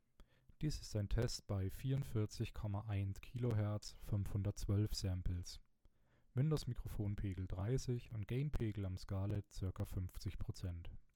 Das Problem: Egal welche Einstellung ich verwende, das Mikrofon knackt, rauscht und plopt, je nachdem wie ich die Buffer Size einstelle wird das besser oder schlechter.
Die Aufnahme war Glücklich, denn selbst dabei knackt es manchmal.